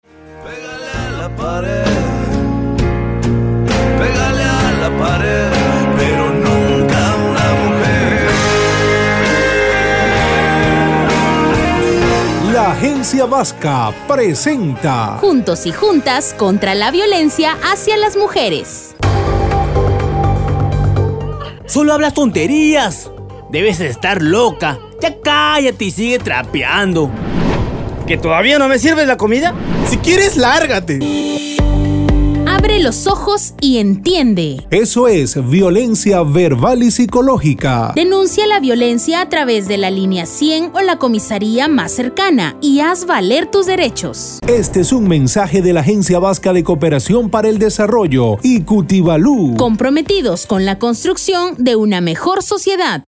Para la grabación de los spots se utilizó talento de nuestra estación radial, en su mayoría se hicieron dramatizaciones con la intención de que el mensaje llegará de una forma más fácil.
SPOT-2-VIOLENCIA-VERBAL_FINAL.mp3